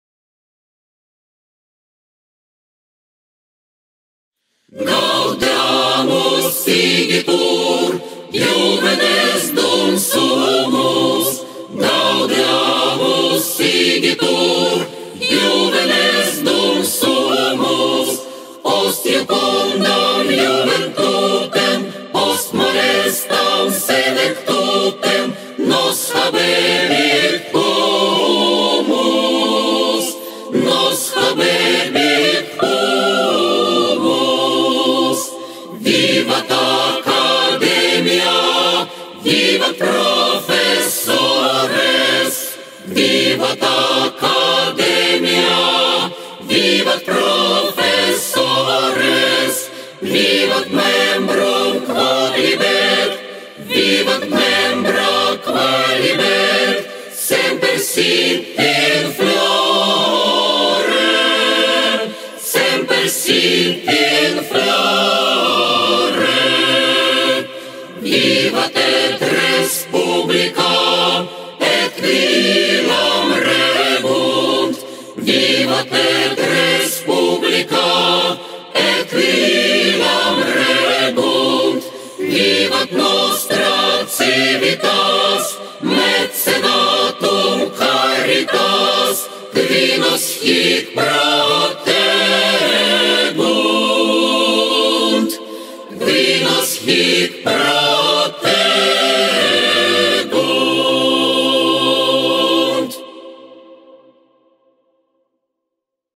для хора
Студенческий гимн